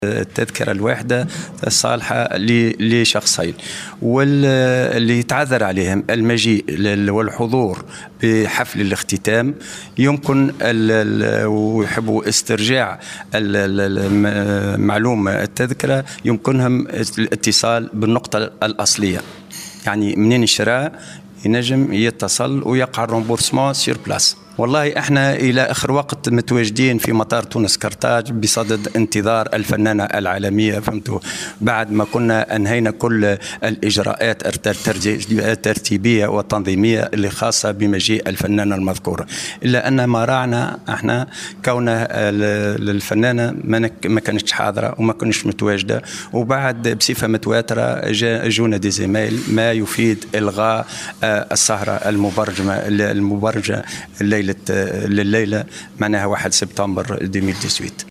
تصريح لإذتعة الجوهرة أف أم